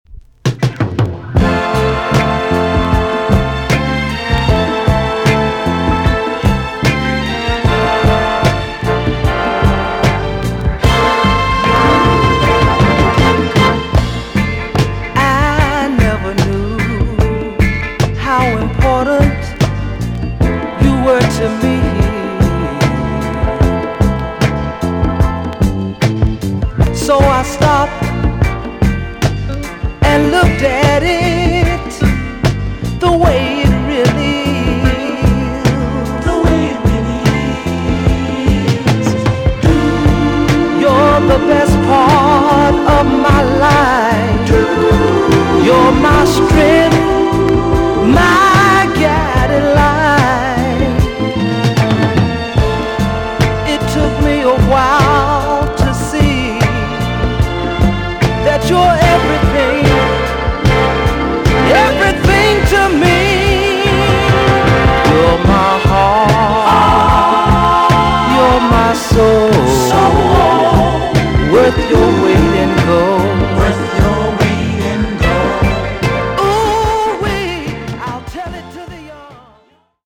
EX-音はキレイです。
1975 , WICKED SWEET SOUL TUNE!!
JAMAICAN SOUL RECOMMEND!!